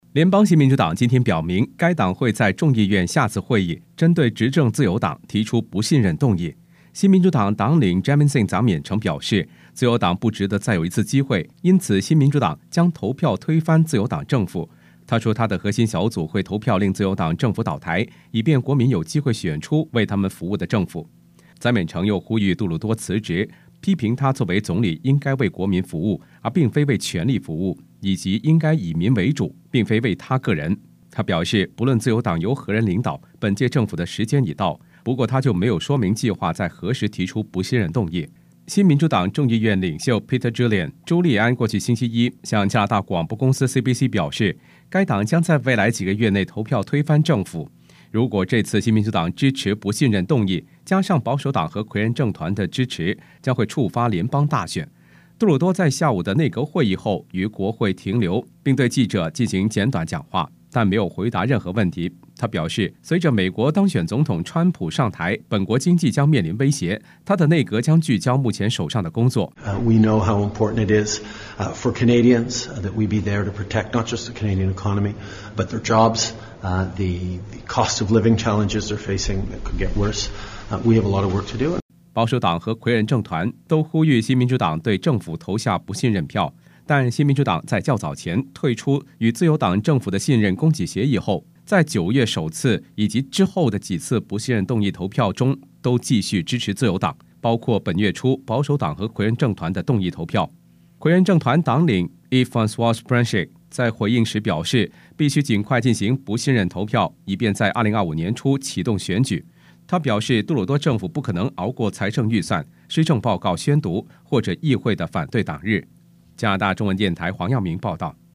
Canada/World News 全國/世界新聞
news_clip_21820_mand.mp3